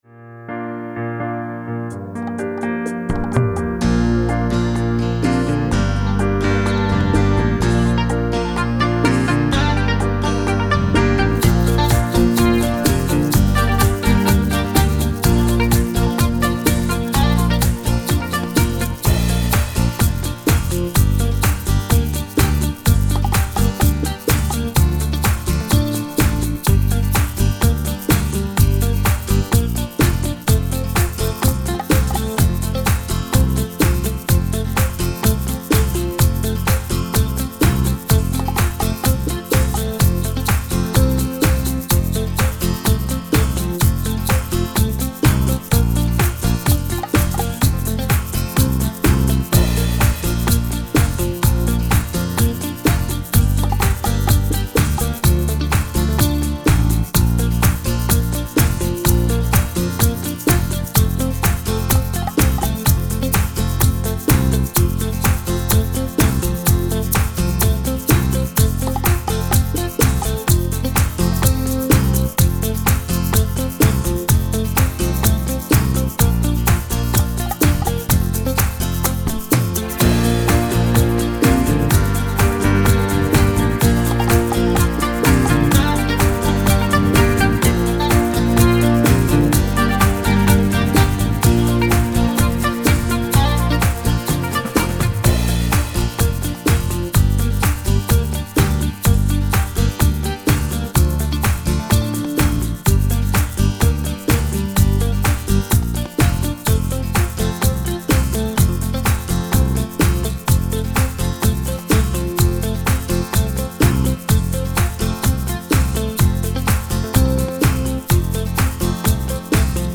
BACHATA